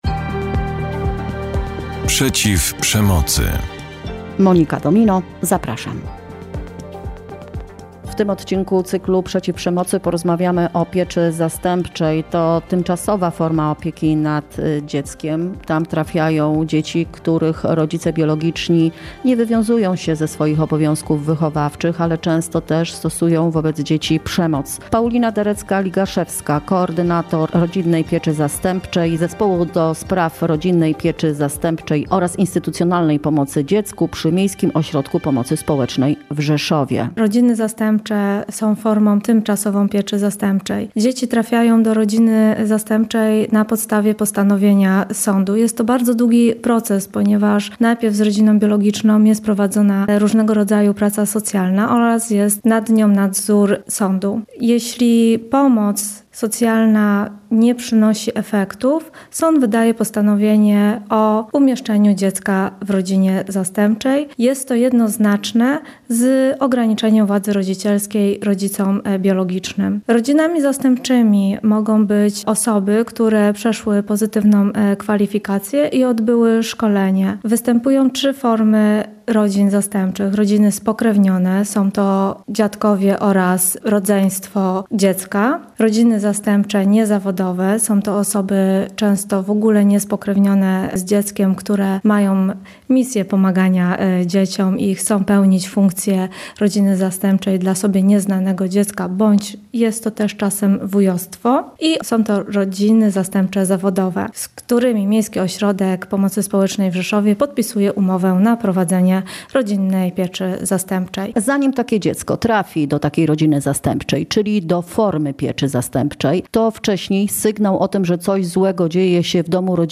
W naszym cyklu również rozmowa z psychologiem o tym, co czują takie dzieci i jak wygląda pomoc specjalistów, by zminimalizować skutki przemocy, której doświadczały w swojej rodzinie.